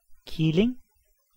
Ääntäminen
IPA: /ʃa.tɔ̃/